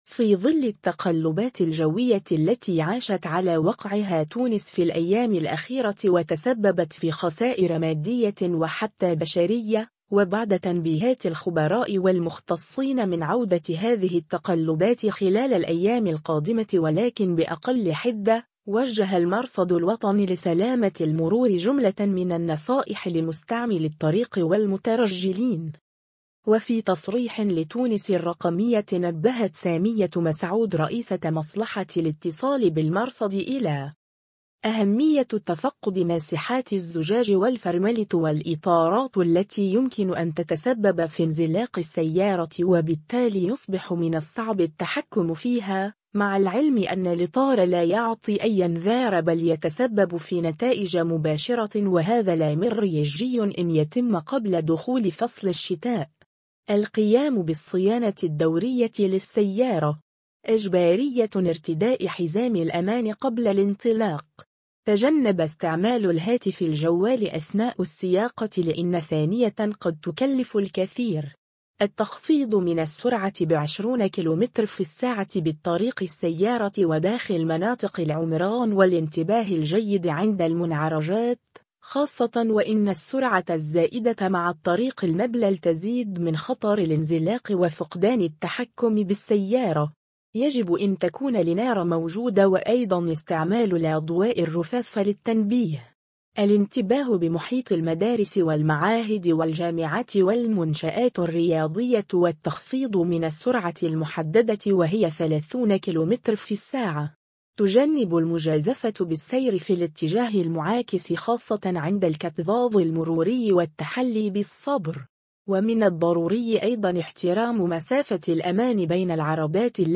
و في تصريح لتونس الرّقمية